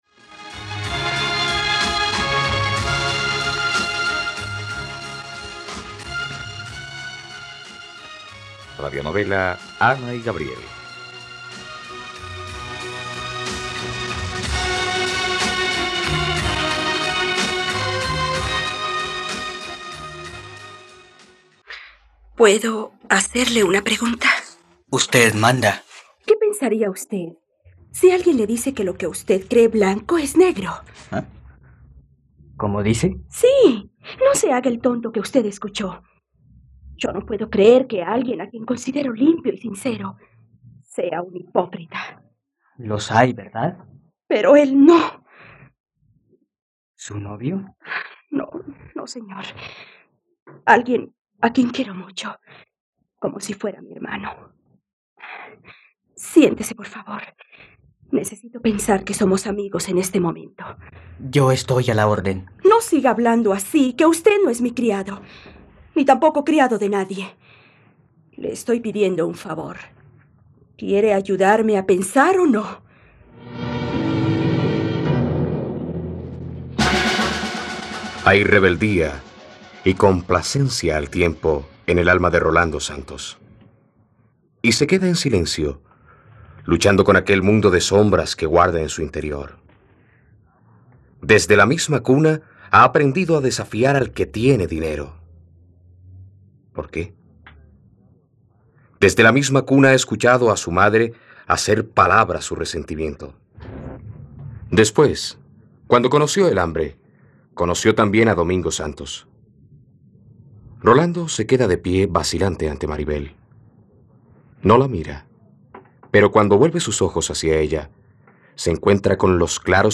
..Radionovela. Escucha ahora el capítulo 18 de la historia de amor de Ana y Gabriel en la plataforma de streaming de los colombianos: RTVCPlay.